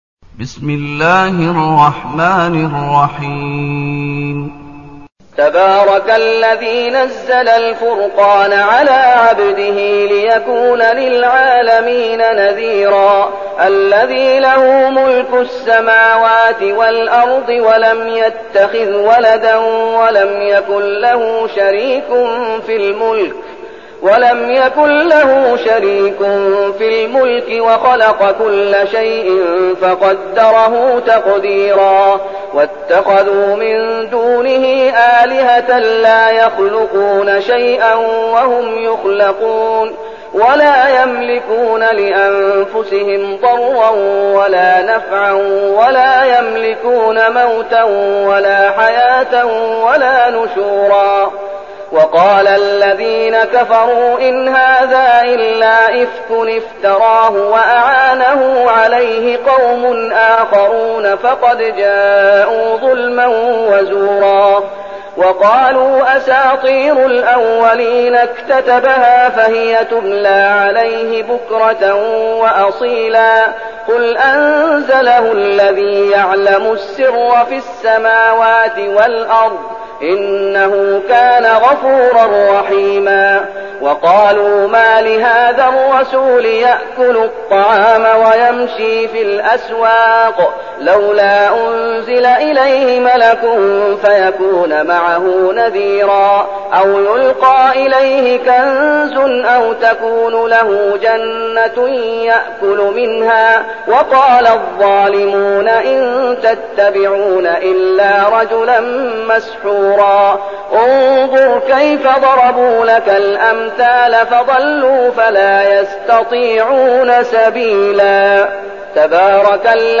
المكان: المسجد النبوي الشيخ: فضيلة الشيخ محمد أيوب فضيلة الشيخ محمد أيوب الفرقان